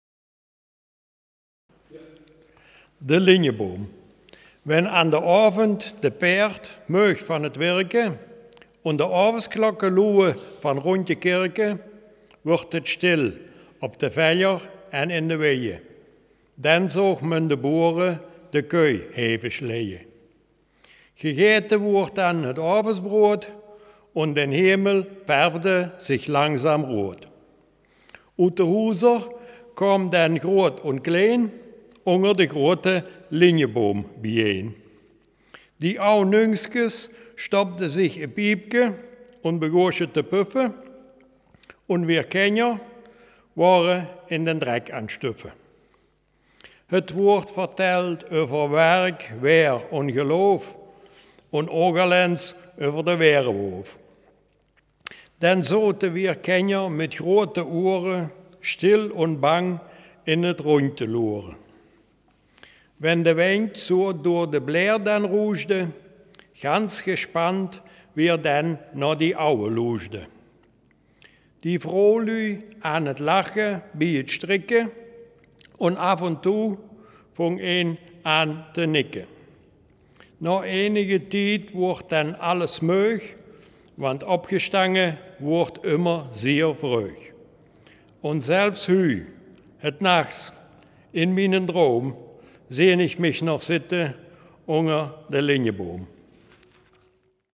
Selfkant-Platt
Geschichte